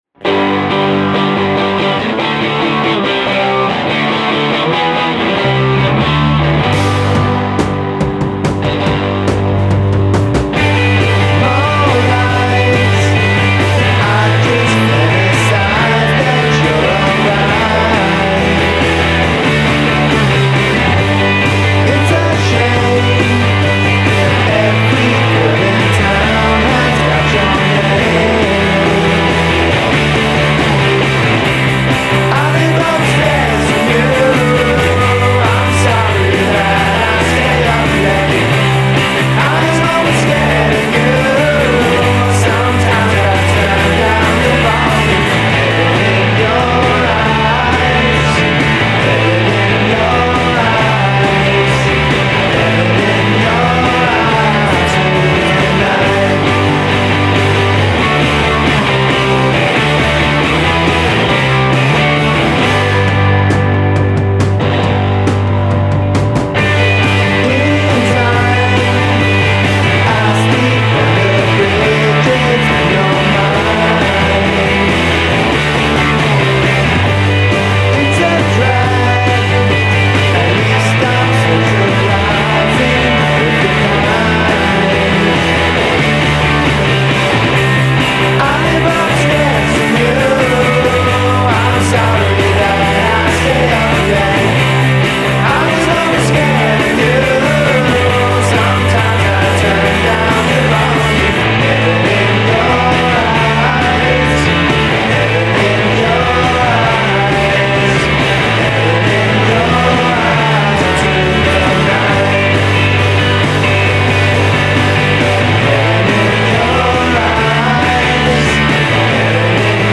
stellar lo-fi power pop
lo-fi power pop gems